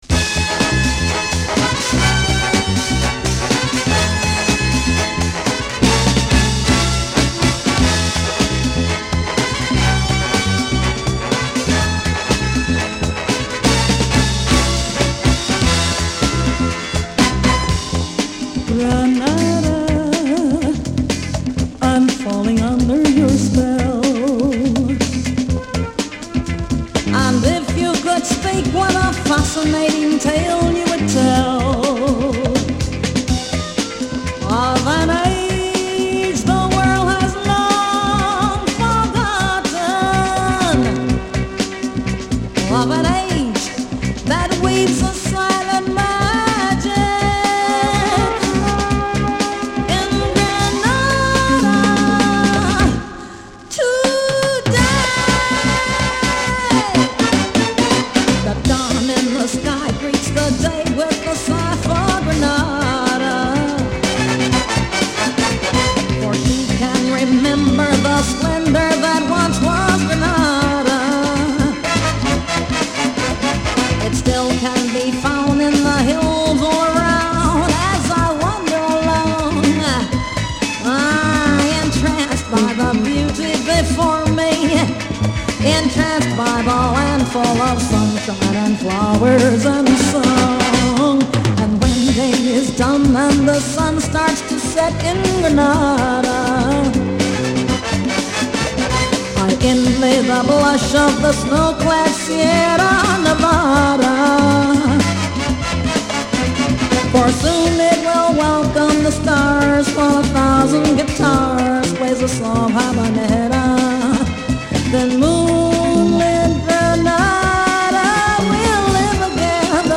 ダイナミックなオーケストラと切れ味の良いブラスを背に
disk : VG+ to EX- （多少チリ音が出ます）